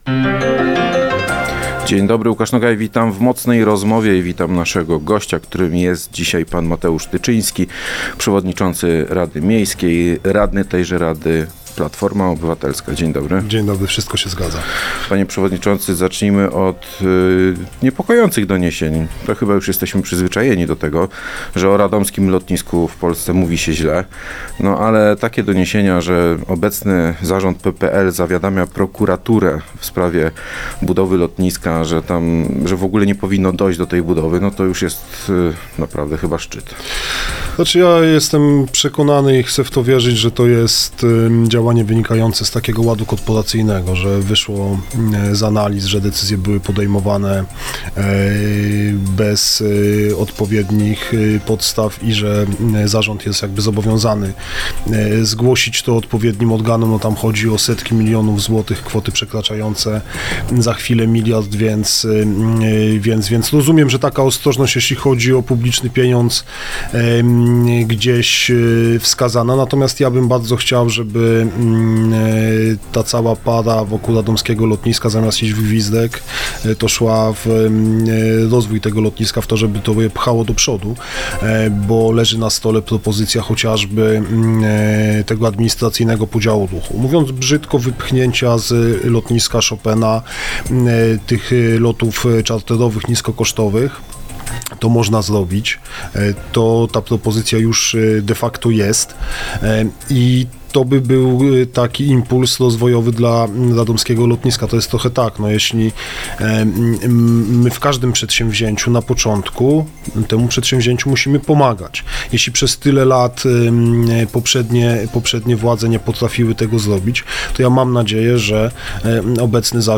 Przewodniczący Rady Miejskiej Mateusz Tyczyński jest gościem